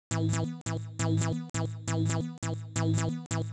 • sharp synth techno sequence.wav
sharp_synth_techno_sequence_Pxl.wav